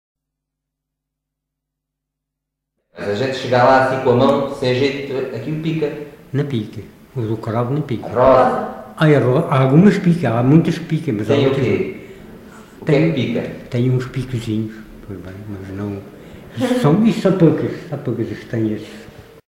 LocalidadePorto da Espada (Marvão, Portalegre)